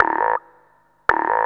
Index of /90_sSampleCDs/Zero-G - Total Drum Bass/Instruments - 3/track64 (Vox EFX)
09-Frog Throat.wav